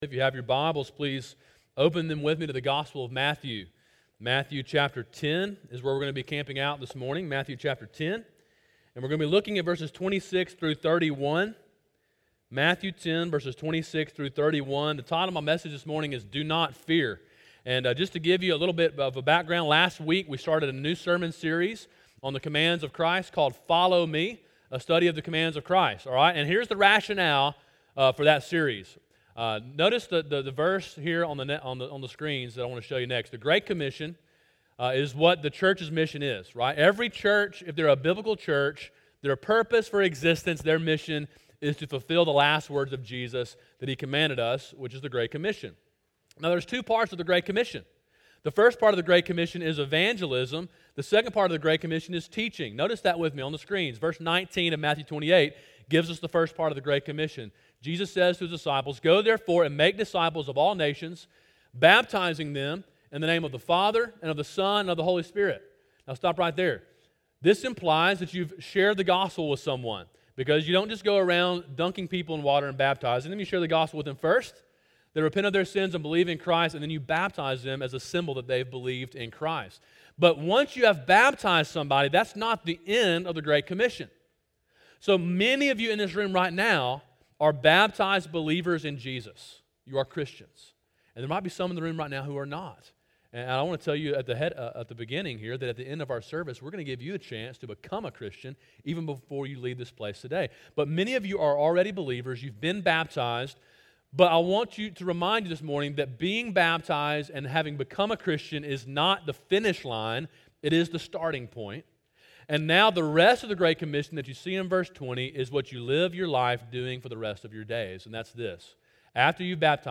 Sermon: “Do Not Fear” (Matthew 10:26-31)
sermon7-08-18.mp3